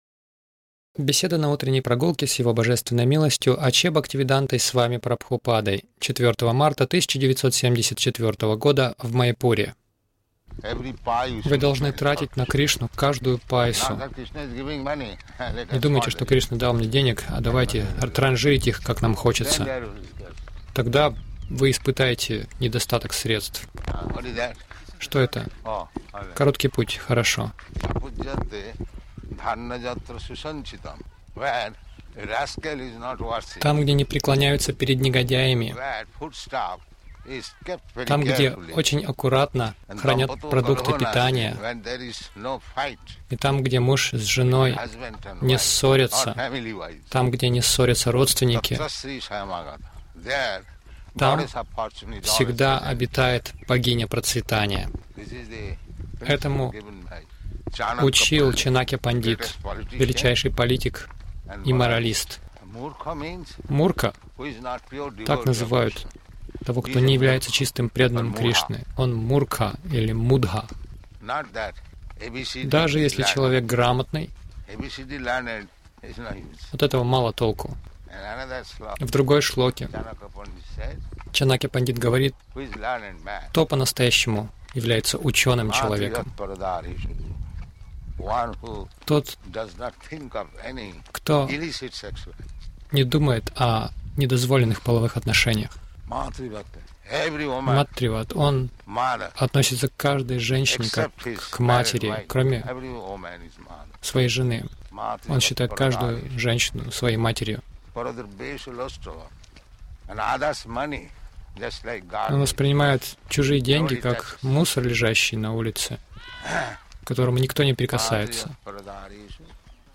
Милость Прабхупады Аудиолекции и книги 04.03.1974 Утренние Прогулки | Маяпур Утренние прогулки — Кто учёный человек Загрузка...